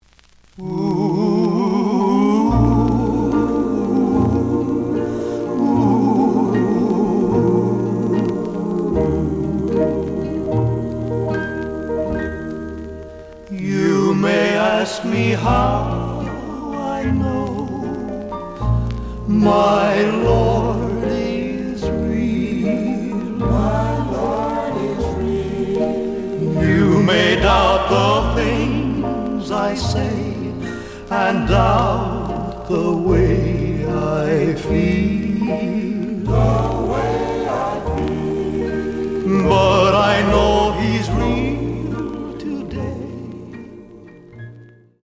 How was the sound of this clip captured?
The audio sample is recorded from the actual item. Looks good, but slight noise on both sides.)